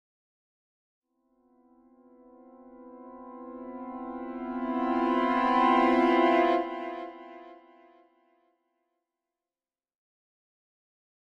Symphonic Strings Suspense Chord 1 - Increasing - Higher